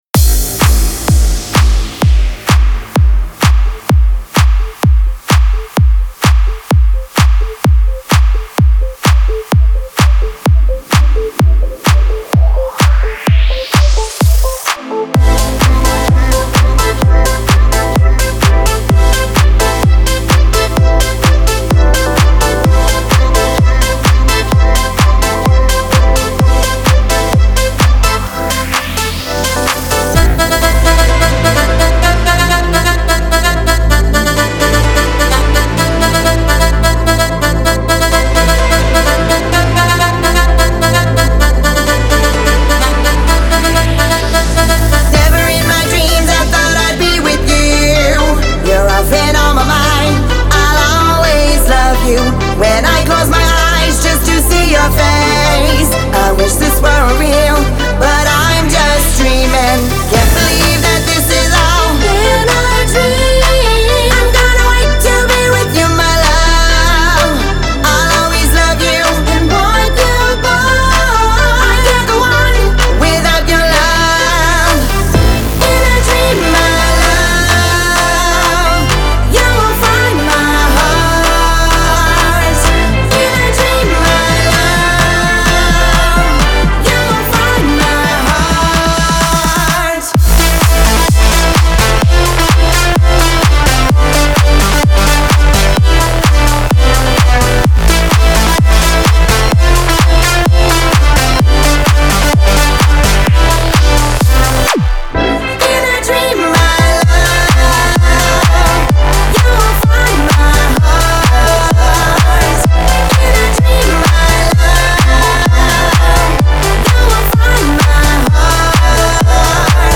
8A - 128 Club House